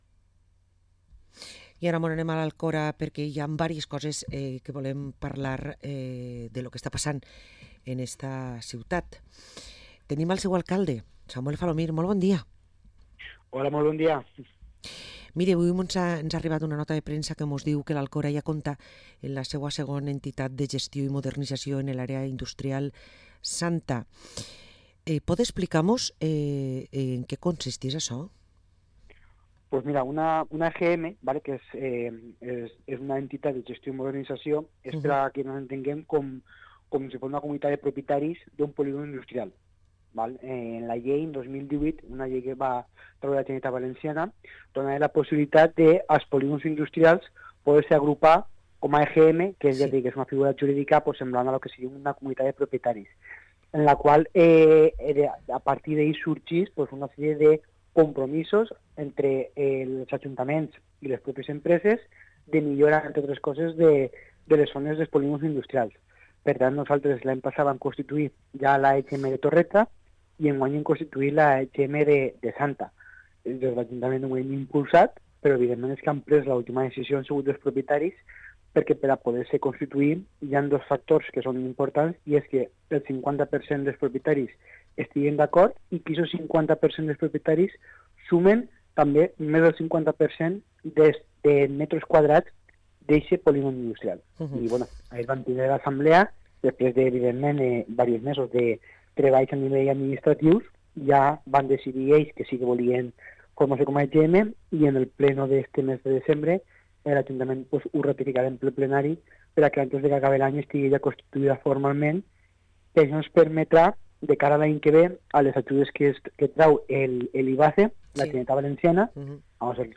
Parlem amb Samuel Falomir, alcalde de l´Alcora